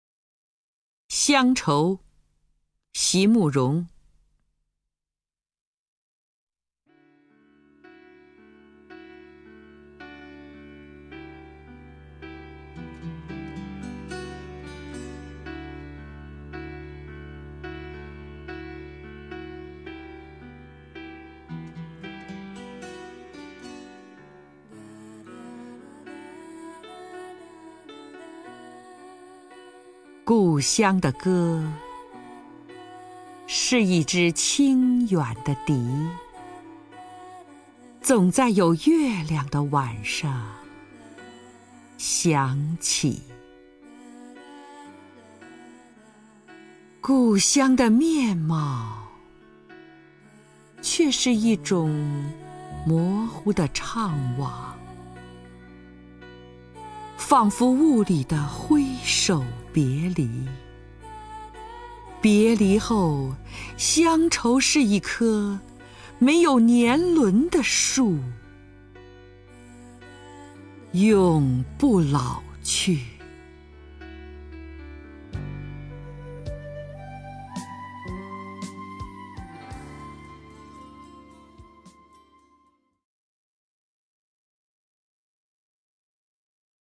首页 视听 名家朗诵欣赏 张筠英
张筠英朗诵：《乡愁》(席慕容)
XiangChou_XiMuRong(ZhangJunYing).mp3